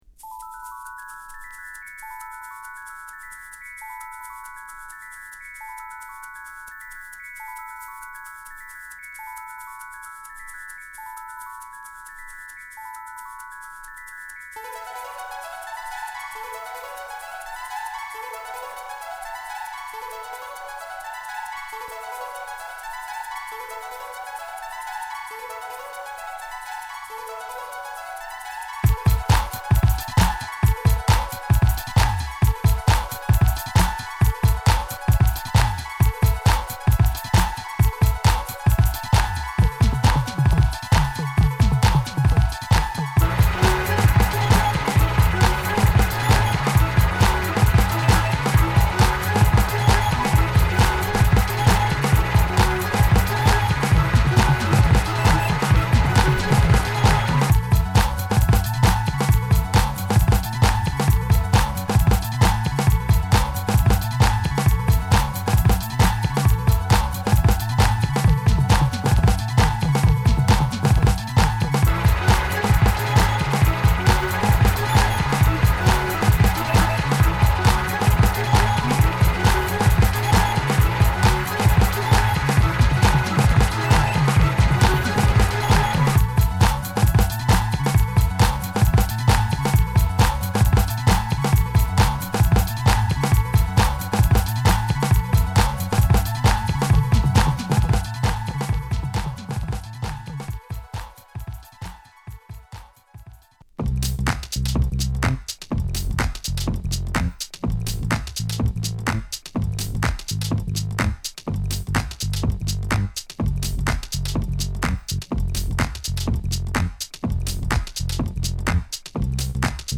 Acid House , Disco , House